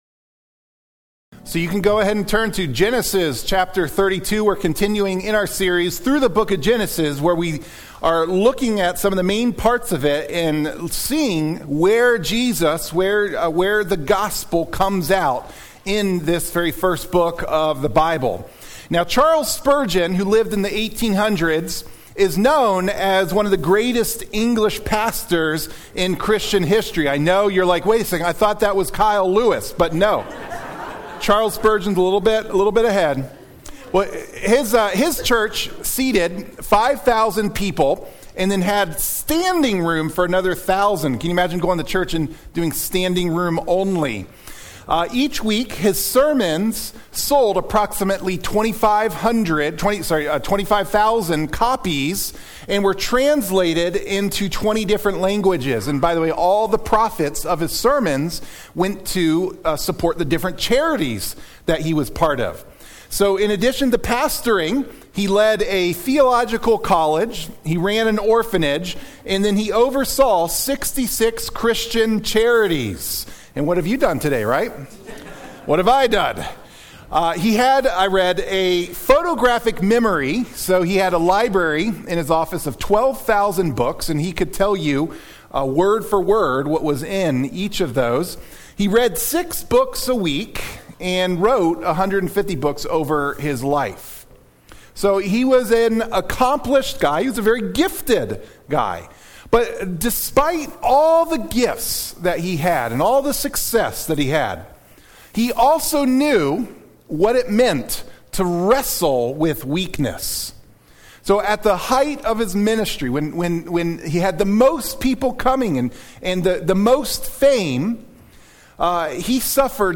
Sermon Clip The message notes for the sermon can be downloaded by clicking on the “save” button.